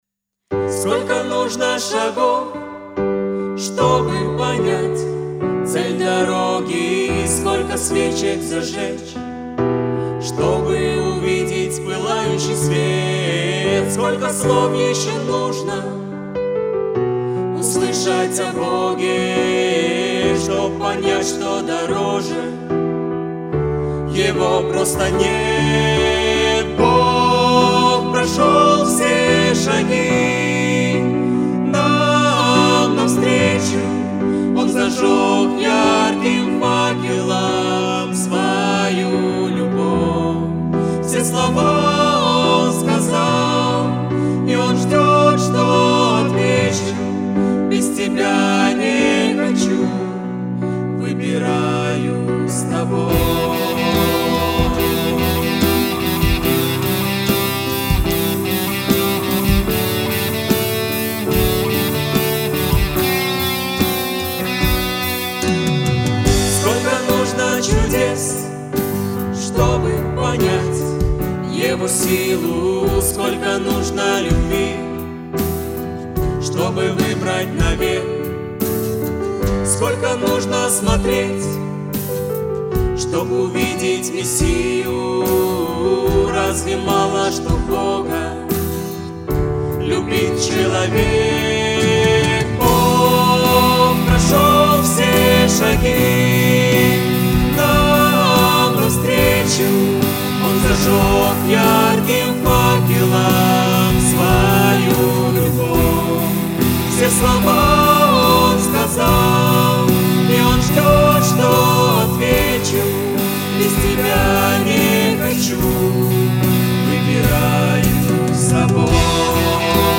песня
165 просмотров 206 прослушиваний 9 скачиваний BPM: 73